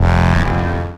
VEC3 FX Athmosphere 23.wav